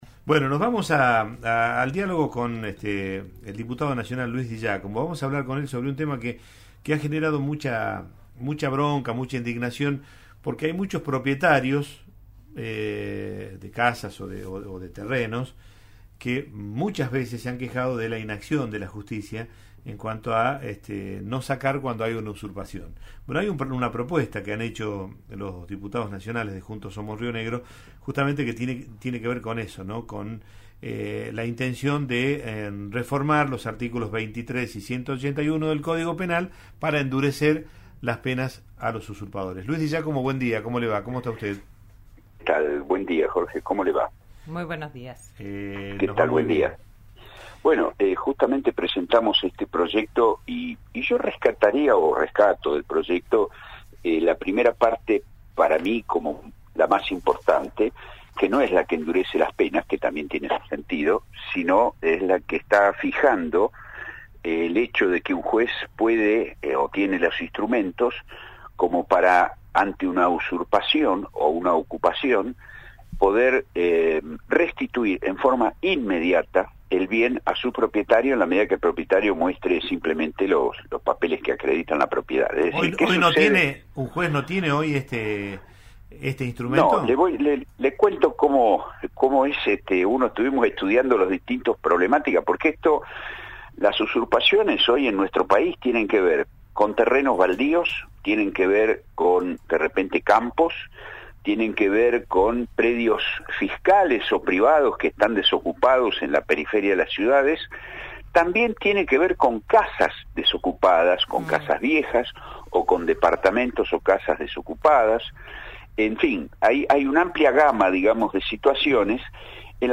En diálogo con «Digan lo que Digan» por RN Radio, aseveró que el oficialismo arremete contra su propio andar y que muchas de sus decisiones causan desconcierto en la ciudadanía.